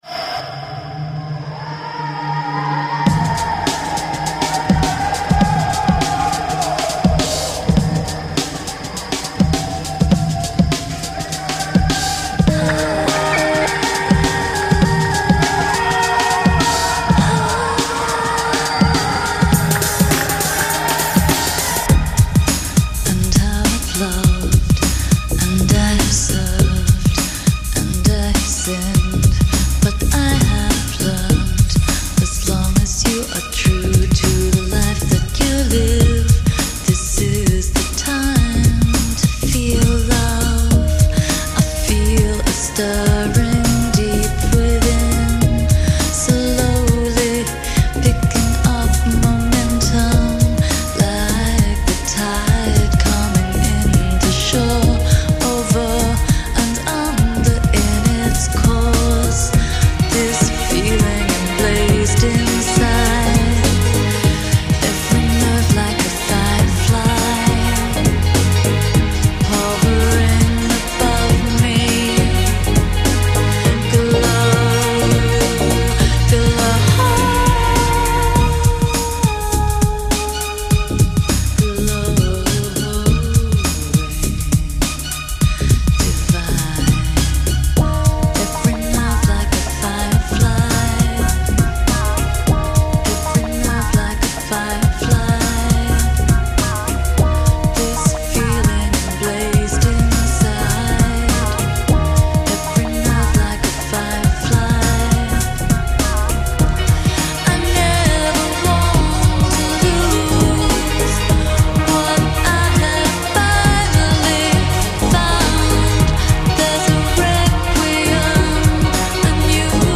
【混音精选】
他们区别于其他Chill Out乐队最明显的地方就是融合了大量的民族音乐元素。